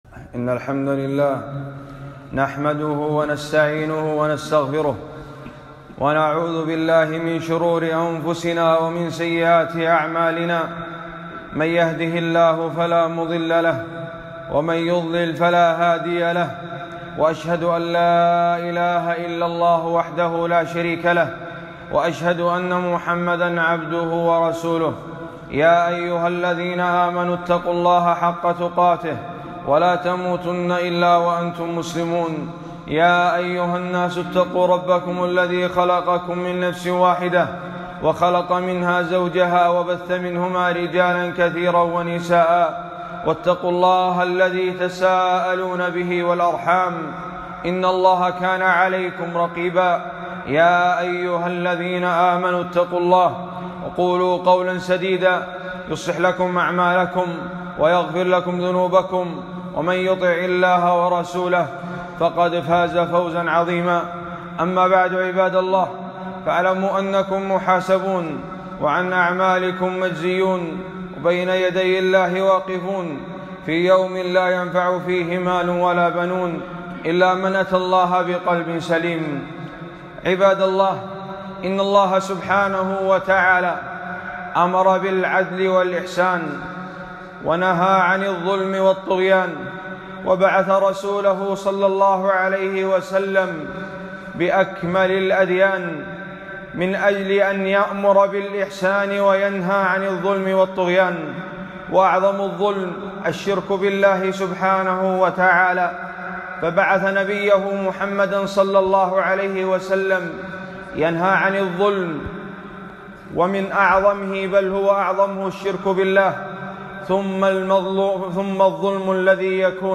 خطبة - شَكْوَيَان كيديتان، ودعوتان مستجابتان